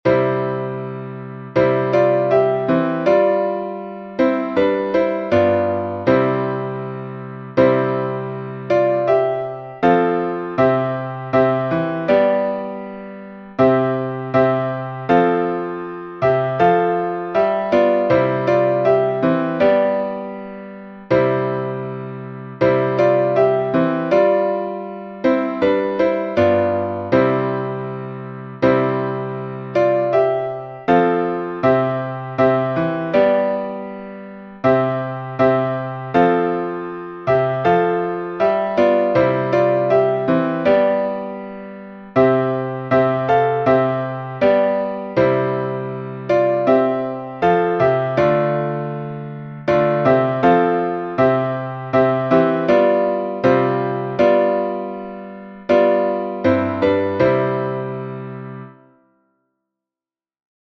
Московский напев